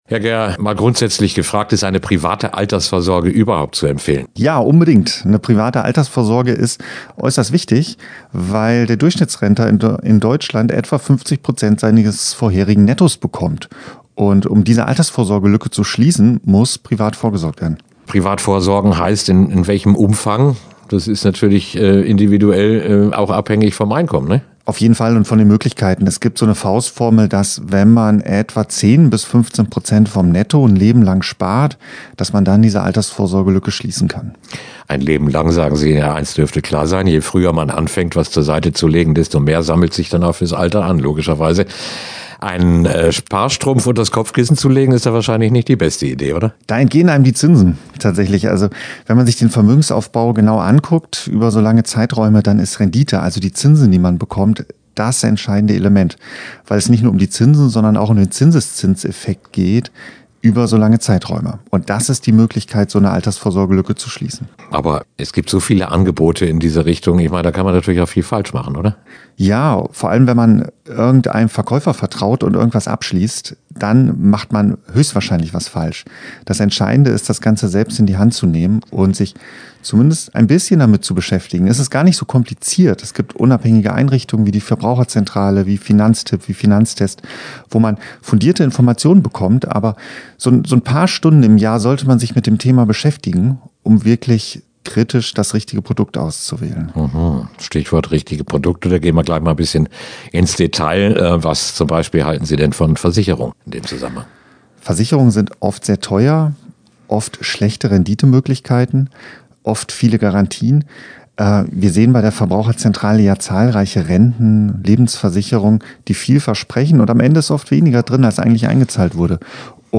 Interview-Verbraucher-24-11-Altersvorsorge.mp3